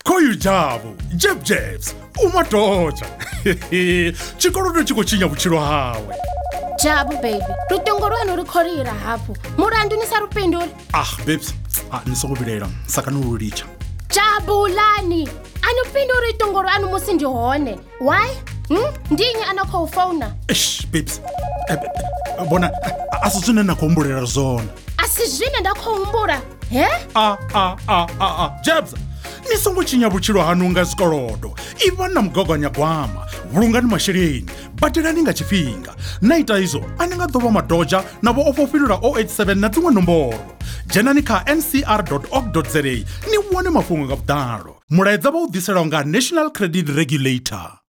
NCR Budget Save Radio Advert - Venda (47sec).wav